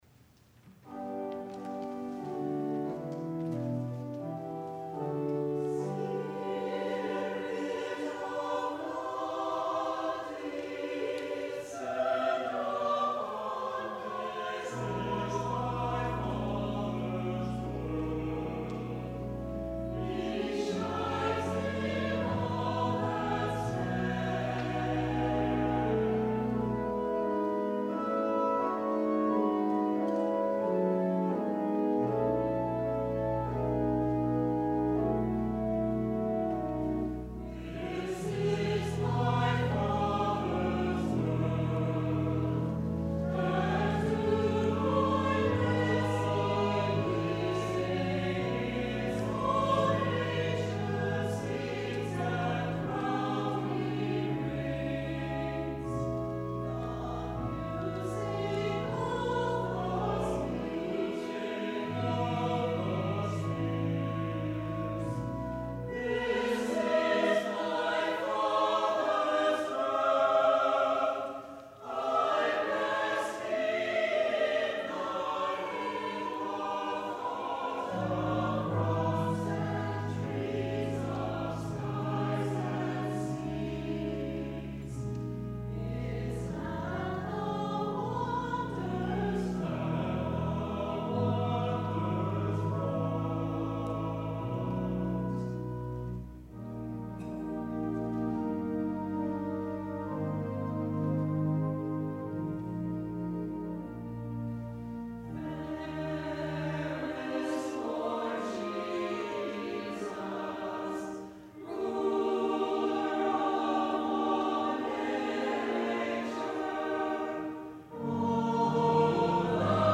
organ
Chancel Choir